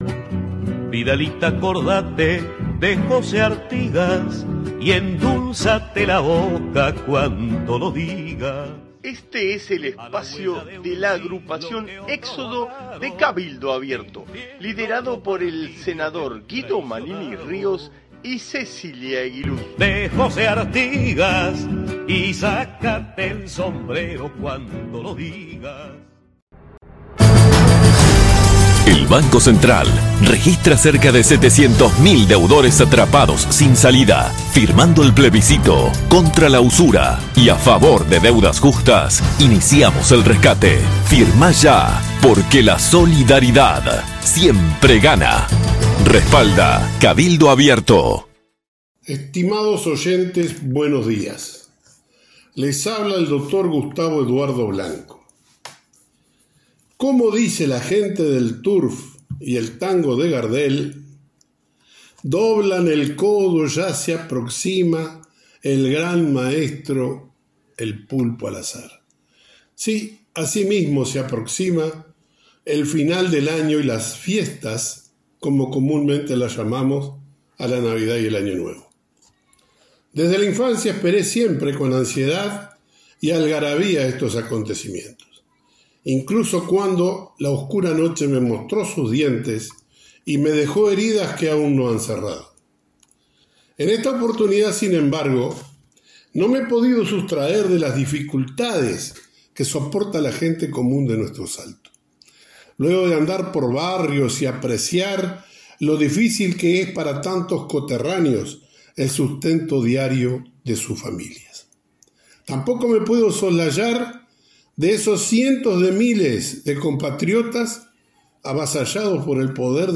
Audición radial de nuestra agrupación para Radio Salto(1120AM) del día 19 de diciembre de 2023.